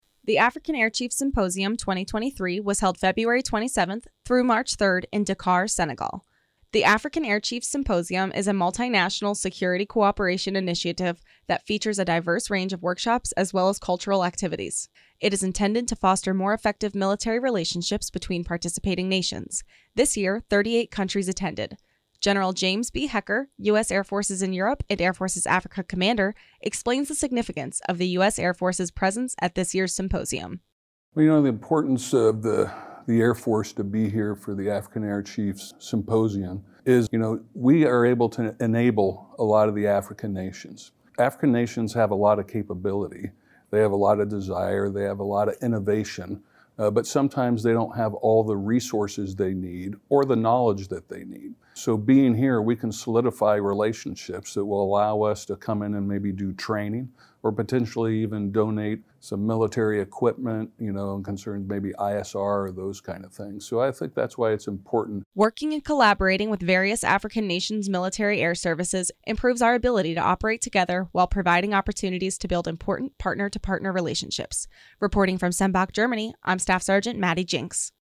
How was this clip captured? THE AFRICAN AIR CHIEFS SYMPOSIUM 2023 WAS HELD FEBRUARY 27TH THROUGH MARCH 3RD, IN DAKAR, SENEGAL.